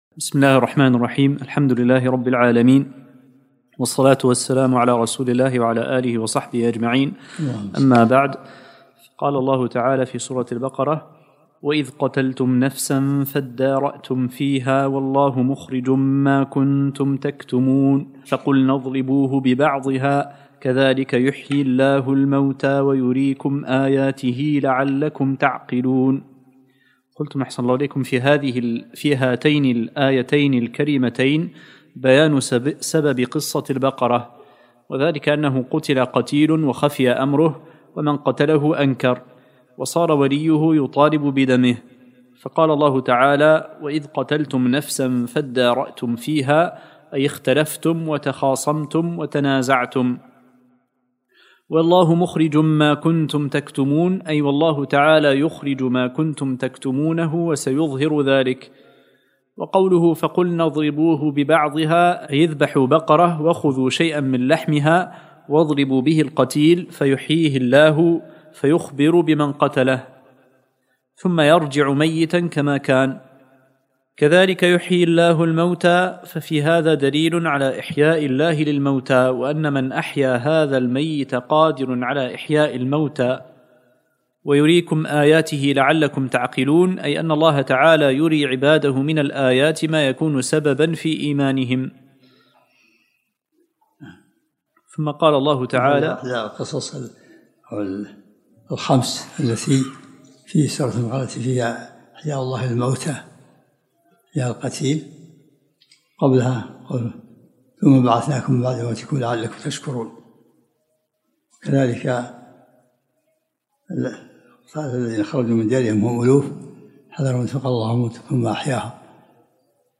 الدرس الخامس من سورة البقرة